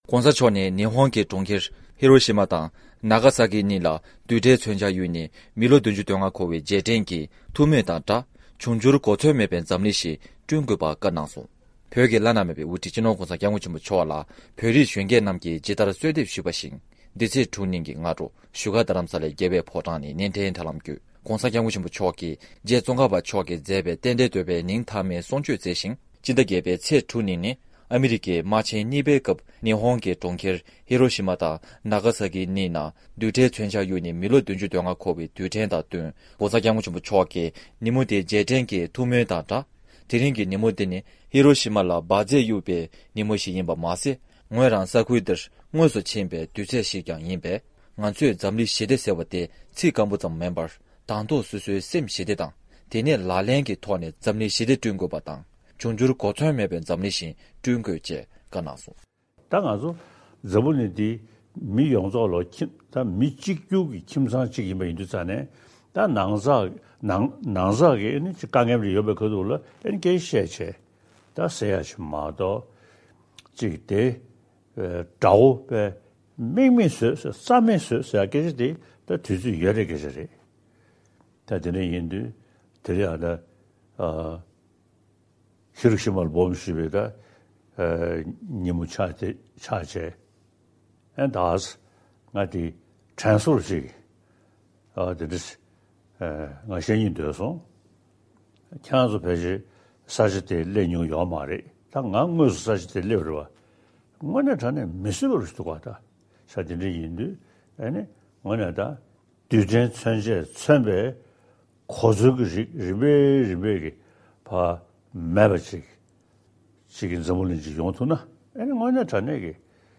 ས་གནས་ནས་བཏང་བའི་གནས་ཚུལ།